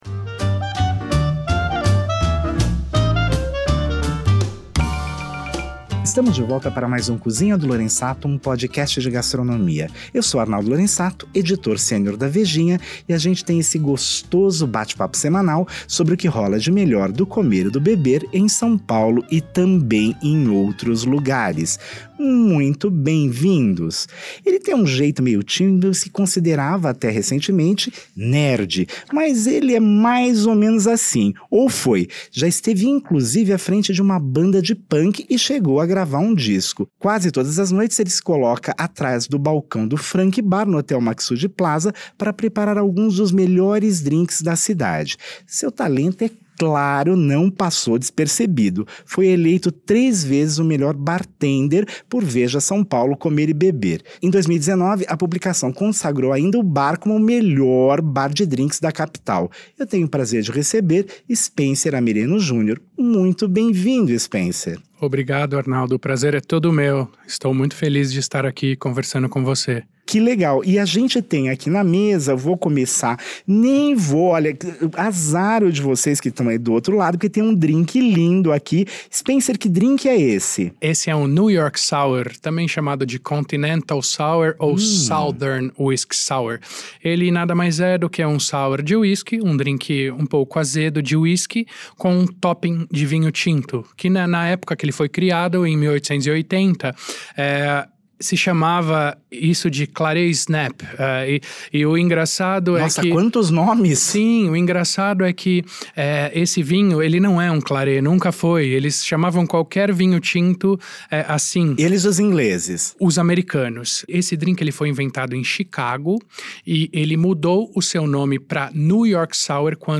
Esse é o último registrado no estúdio do sétimo andar da Editora Abril, na Marginal Tietê.